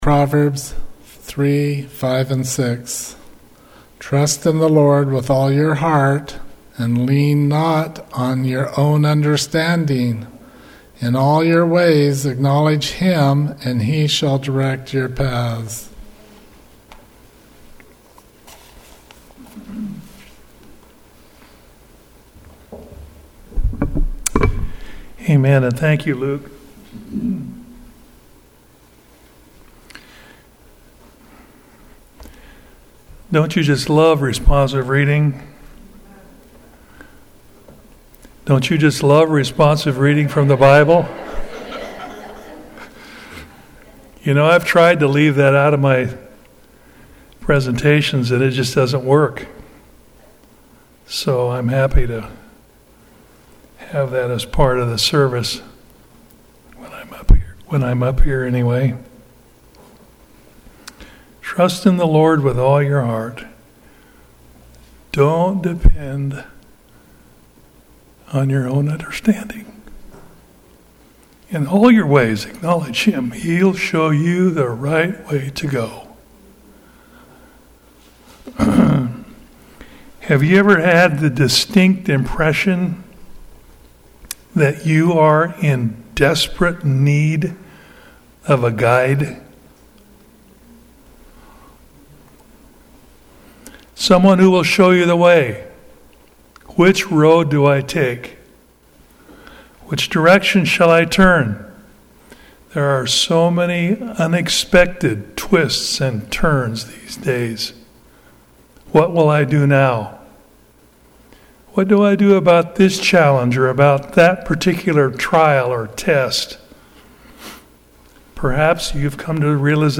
Sermons and Talks 2024